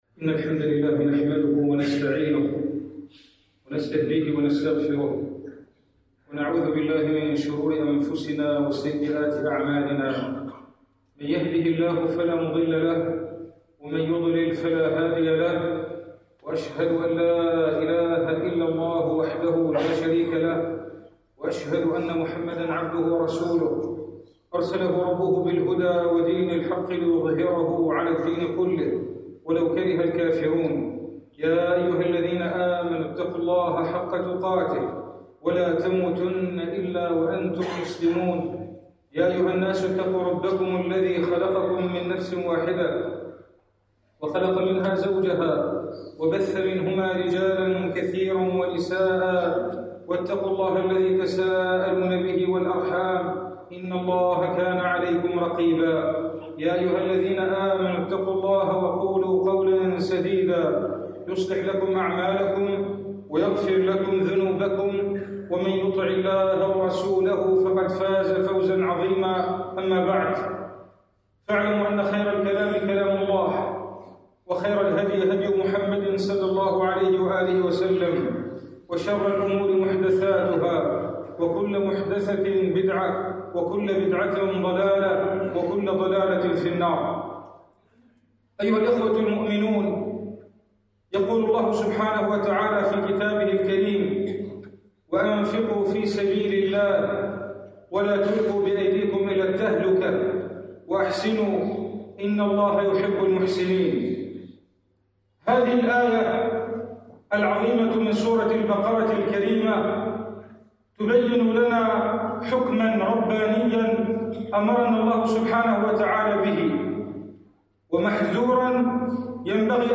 خطبة الجمعة
في جامع القلمون الكبير (البحري)